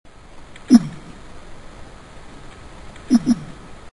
Sounds Made by Silurus glanis
Type of sound produced barks, thumps, escape sounds
Sound production organ pneumatic duct & swim bladder
Sound mechanism not known but probably vibration of swim bladder & pneumatic duct caused by quick contraction of associated muscles
Behavioural context spontaneous nocturnal sound production
Remark outdoor recording late at night (freshwater lake), of a single specimen (sex unknown), species-specific sounds.